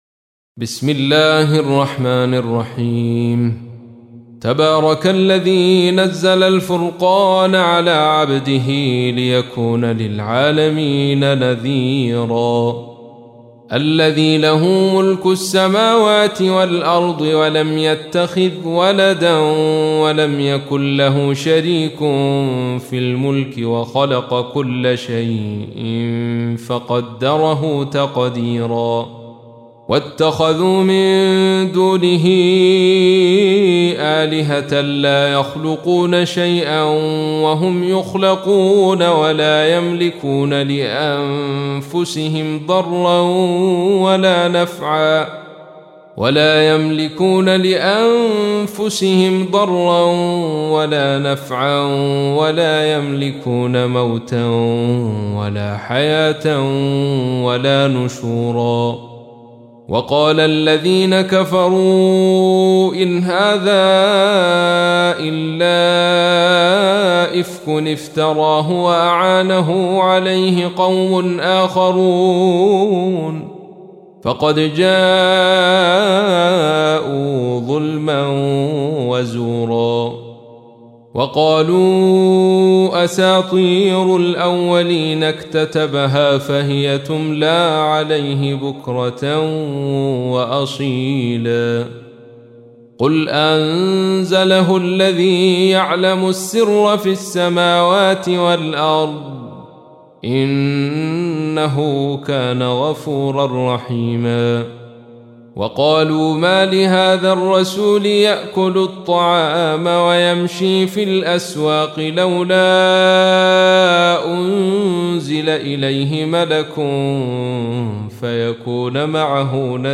تحميل : 25. سورة الفرقان / القارئ عبد الرشيد صوفي / القرآن الكريم / موقع يا حسين